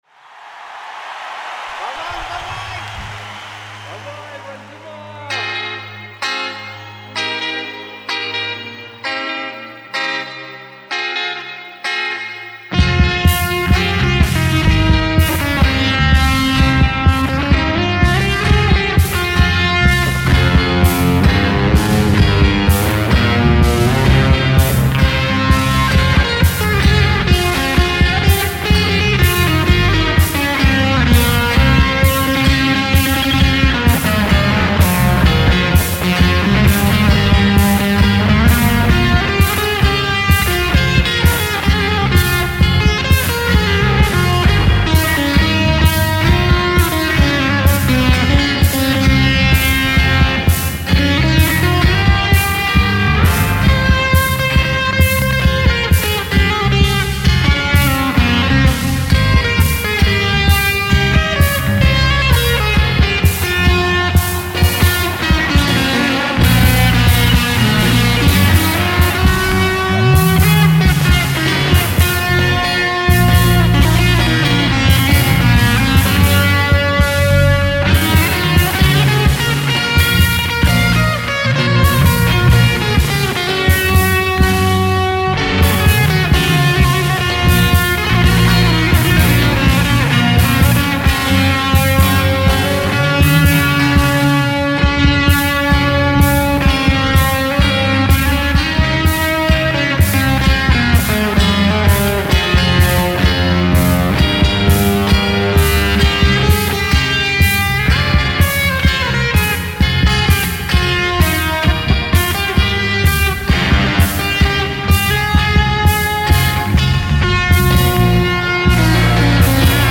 Со своей стороны , надеюсь порадую кого-то вот такой, гитарной вещицей) Где на хороший, конкретный, полноразмерный кирпич, порою)) Вложения Не дала....mp3 Не дала....mp3 7,6 MB · Просмотры: 1.018